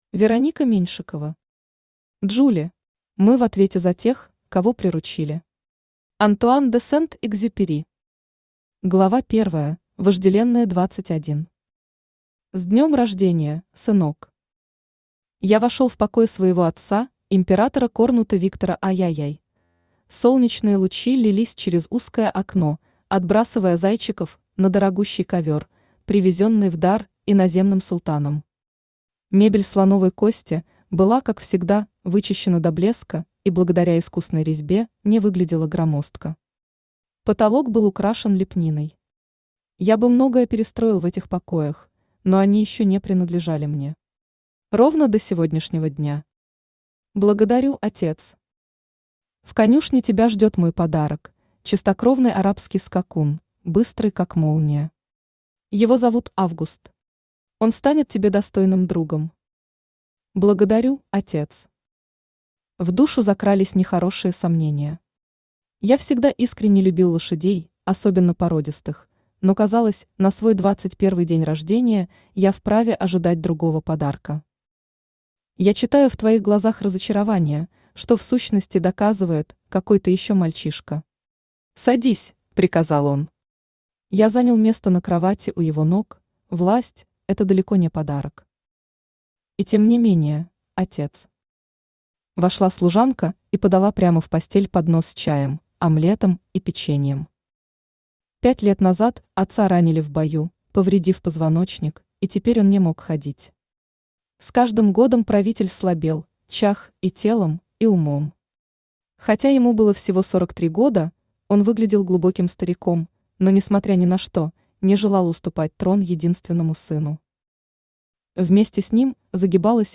Аудиокнига Джули | Библиотека аудиокниг
Aудиокнига Джули Автор Вероника Сергеевна Меньшикова Читает аудиокнигу Авточтец ЛитРес.